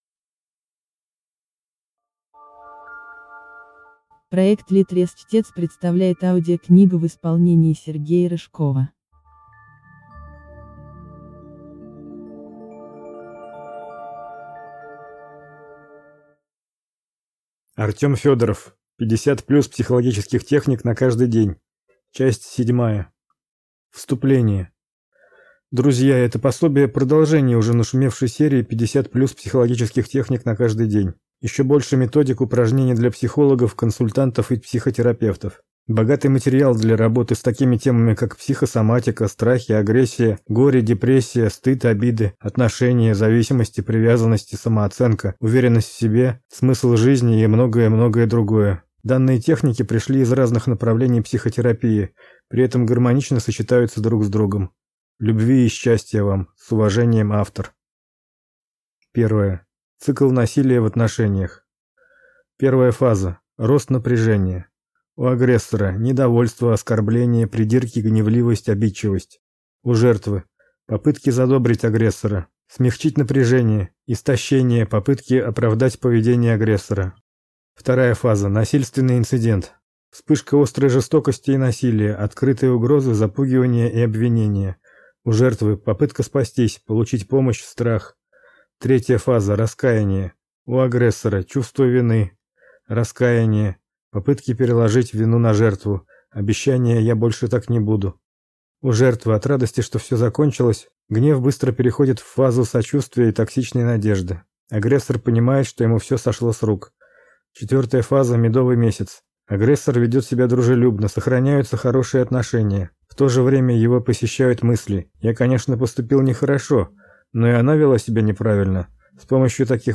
Аудиокнига 50+ психологических техник на каждый день. Часть 7 | Библиотека аудиокниг